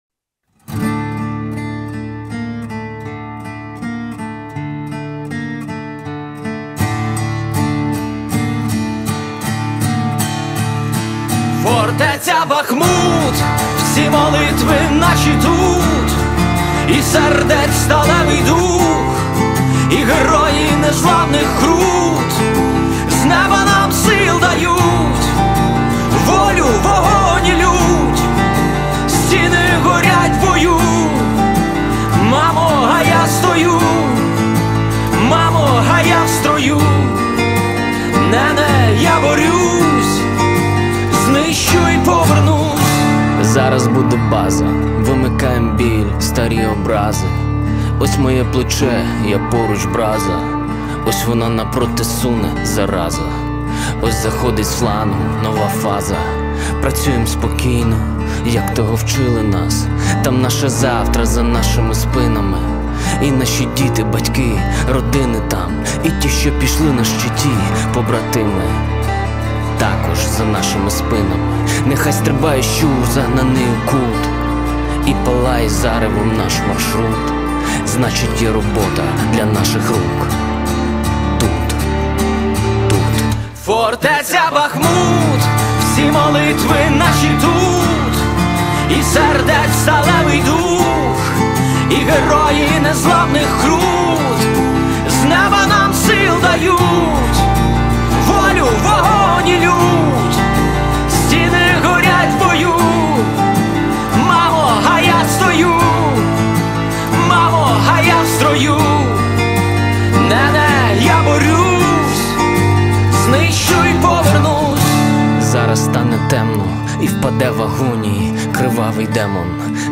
акустическая версия